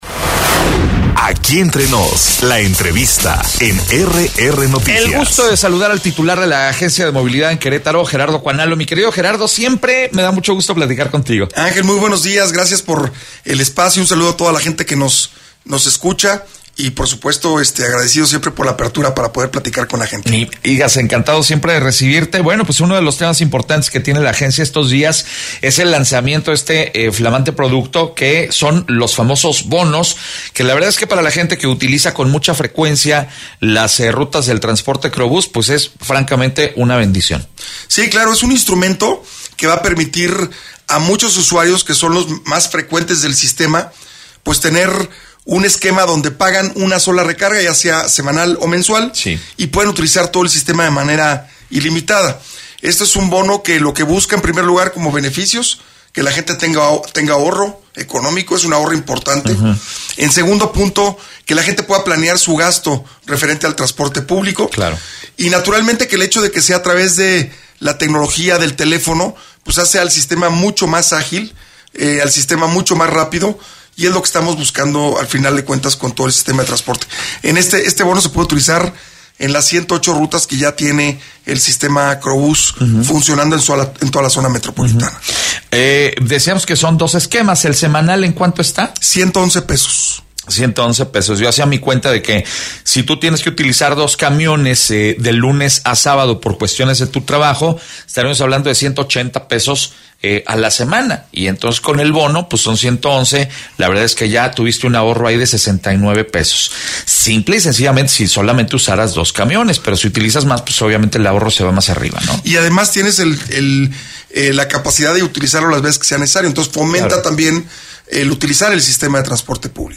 Entrevistas Podcast Gran éxito del bono QroBús: Gerardo Cuanalo.